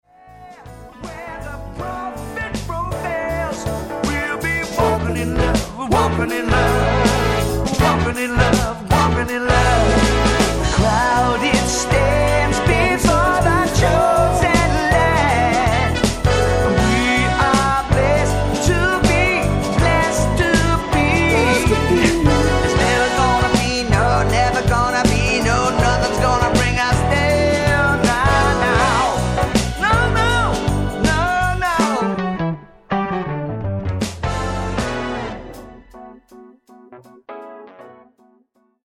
フリー・ソウル・バンド